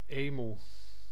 Ääntäminen
Ääntäminen Haettu sana löytyi näillä lähdekielillä: hollanti Käännös Konteksti Ääninäyte Substantiivit 1. émeu {m} eläintiede France (Île-de-France) Paris Muut/tuntemattomat 2. émeus {m} France (Île-de-France) Suku: m .